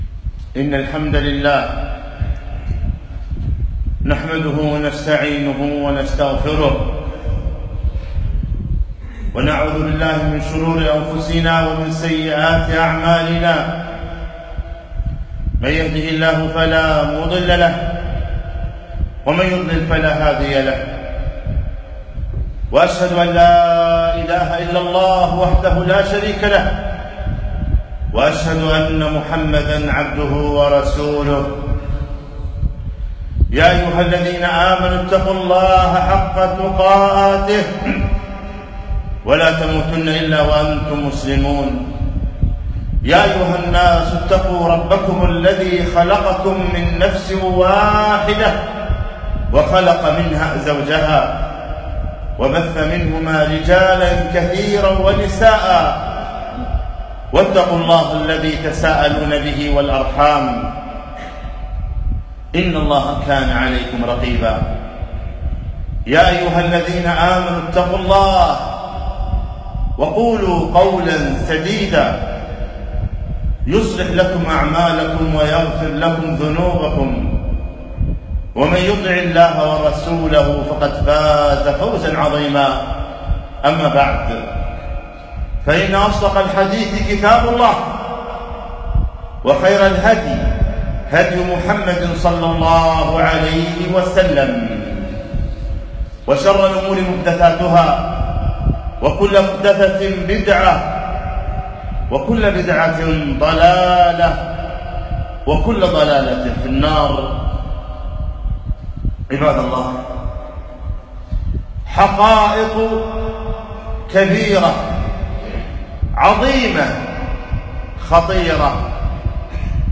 خطبة - عش ماشئت فإنك ميت